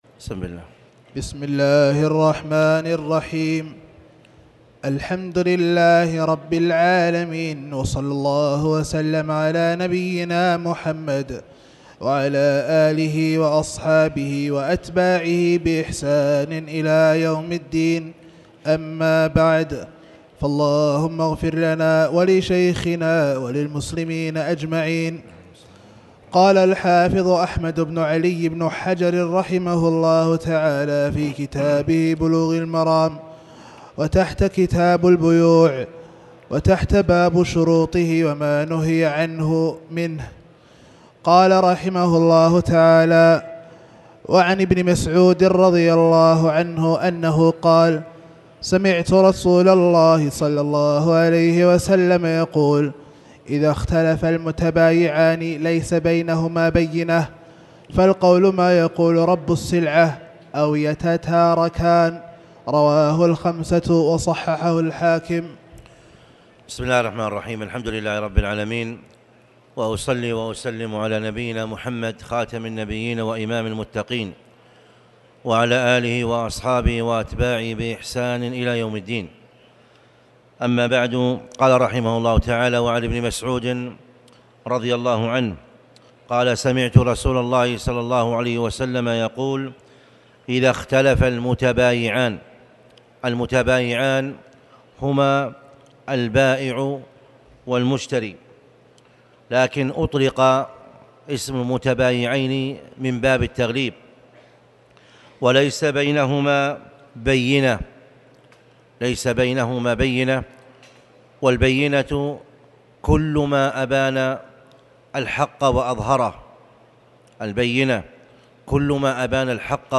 تاريخ النشر ٢٤ محرم ١٤٤٠ هـ المكان: المسجد الحرام الشيخ